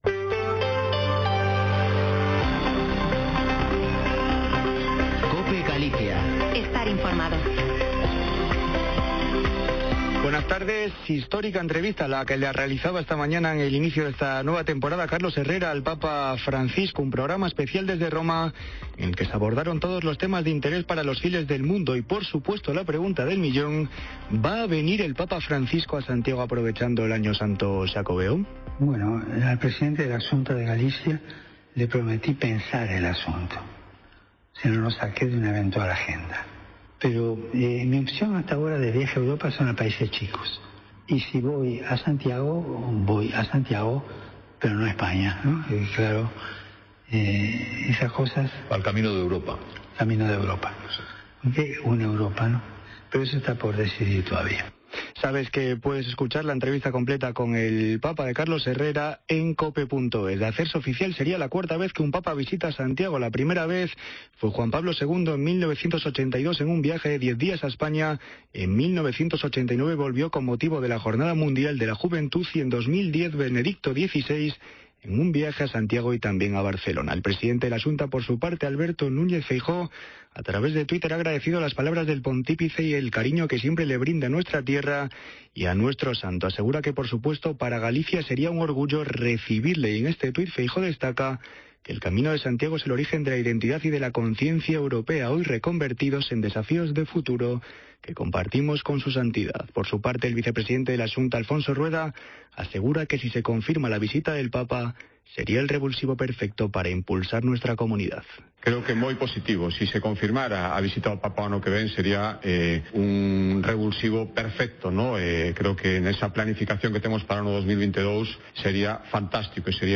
Informativo Mediodía en Cope Galicia 1/09/2021. De 14.48 a 14.58h